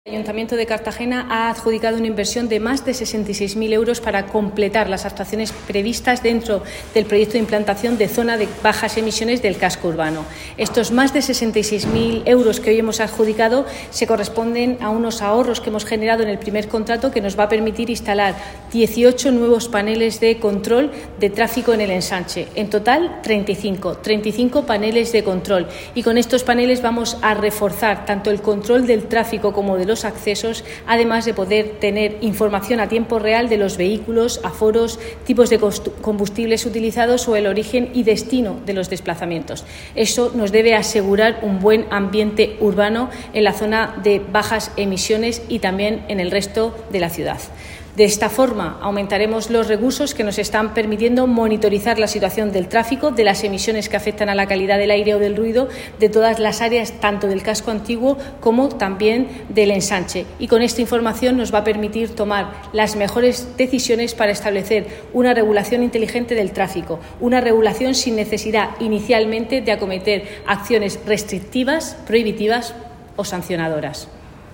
Enlace a Declaraciones de Cristina Mora, concejala delegada de Movilidad y Proyectos Europeos, sobre los paneles de control para monotizar el tráfico